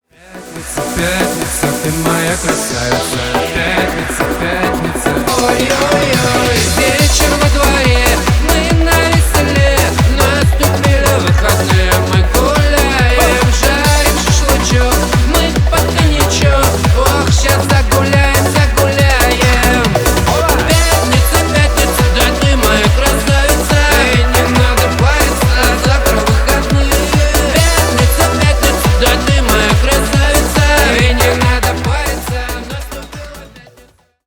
Поп Музыка
весёлые